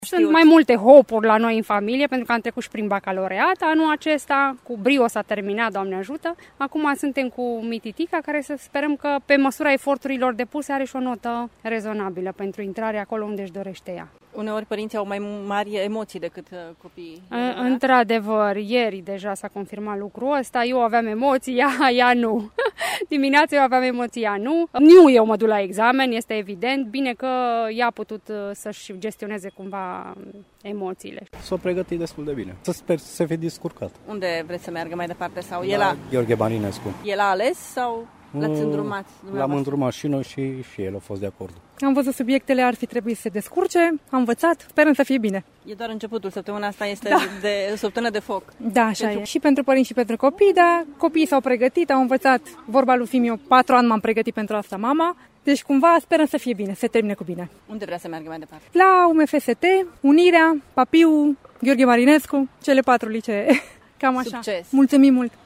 Mai multe emoții decât elevii le-au avut, ca de obicei, părinții: